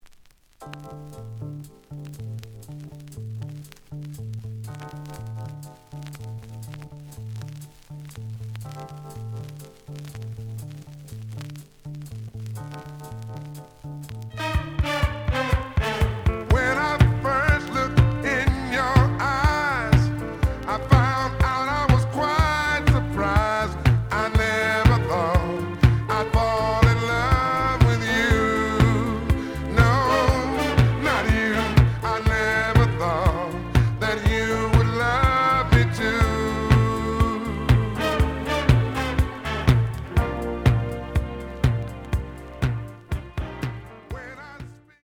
The audio sample is recorded from the actual item.
●Genre: Disco
Looks good, but slight noise on beginning of both sides.